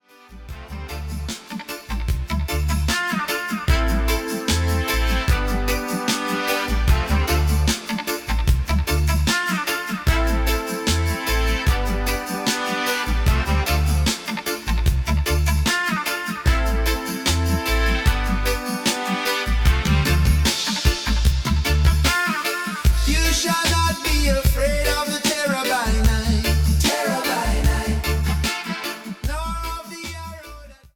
Artist/Performer: (singer/band) – AI